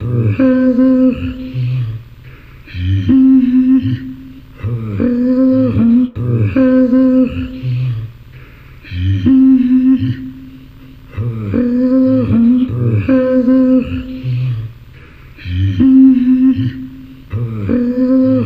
078 Nasal Voices-C.wav